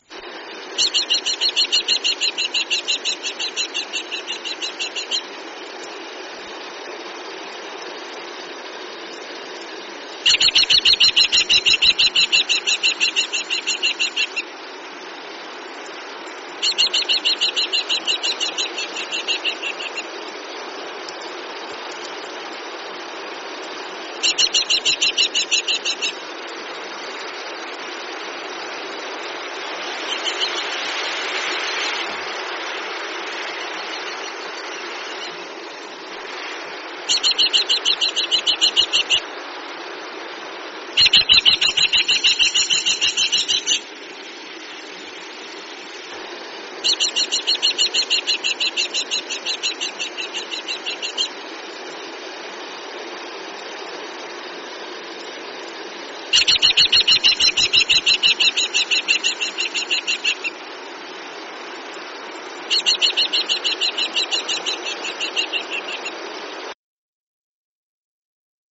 NZ Falcon
Kārearea Call
karearea.mp3